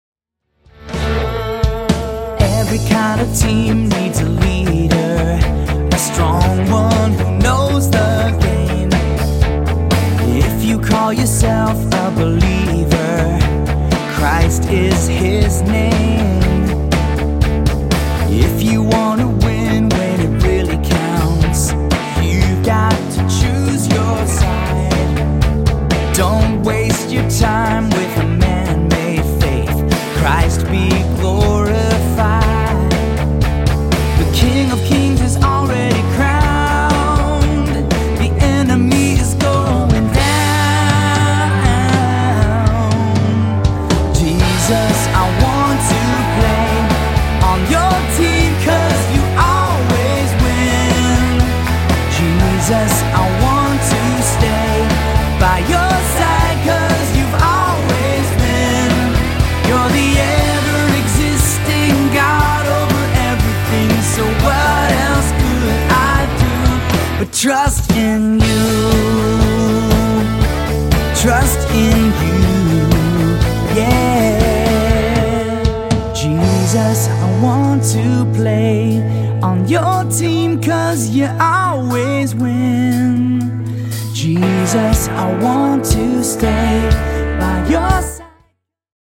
energetic praise and worship music